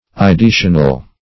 ideational - definition of ideational - synonyms, pronunciation, spelling from Free Dictionary
Ideational \I`de*a"tion*al\, a.
ideational.mp3